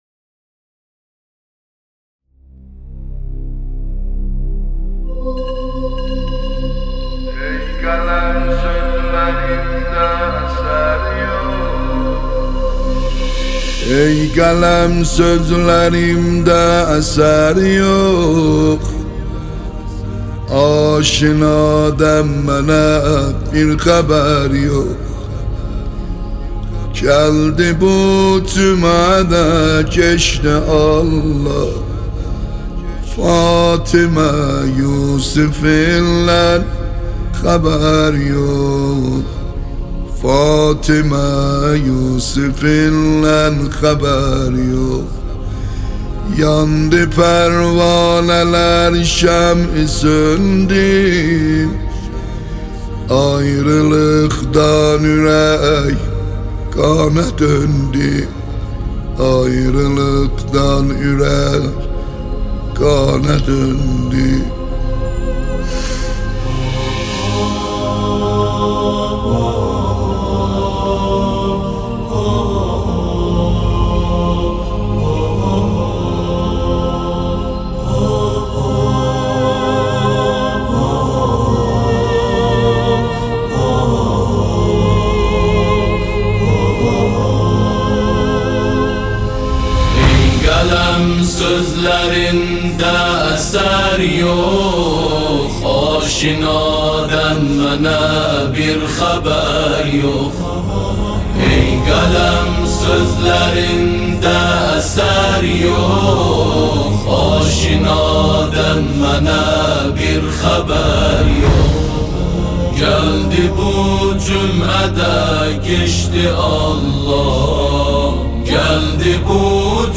گروه تواشیح